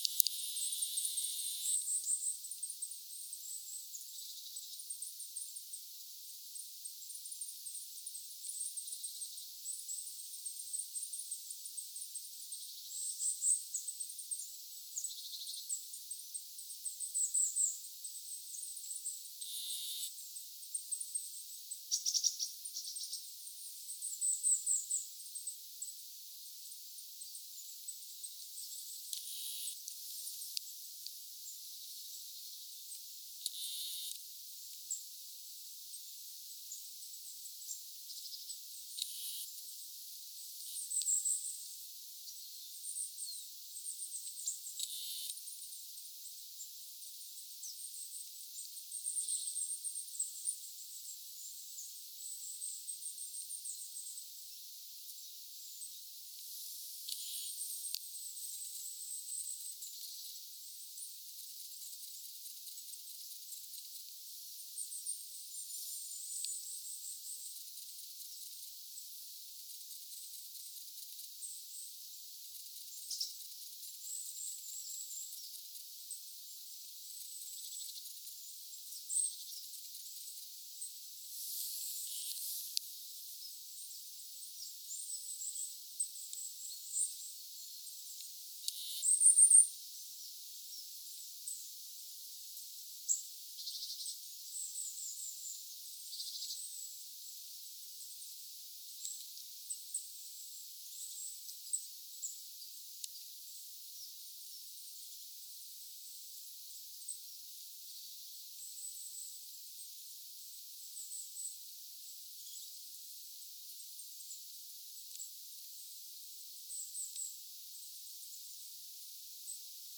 hippiäisten ääntelyä saaressa
hippiaisten_aantelya_saaressa.mp3